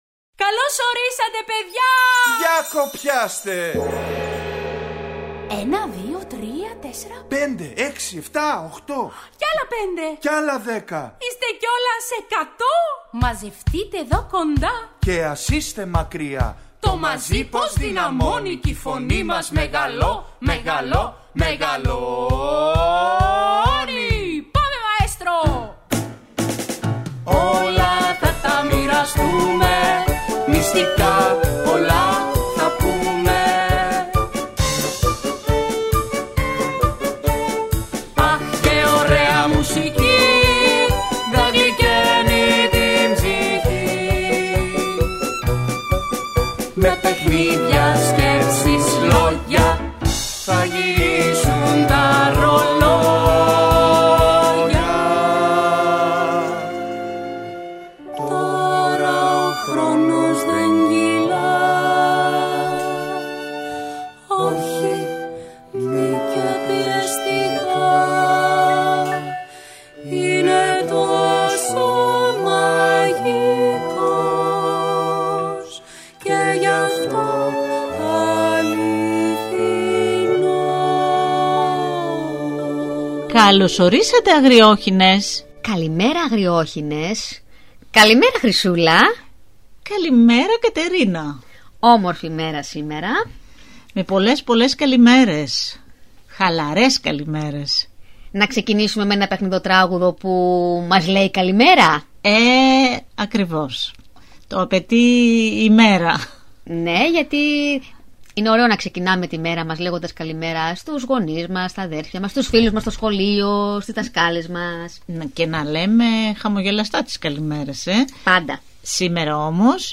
Ακούστε στην παιδική εκπομπή ‘’Οι Αγριόχηνες’’ το παραμύθι “Ο Κήπος της Ελπίδας” του Δημήτρη Τάρταρη.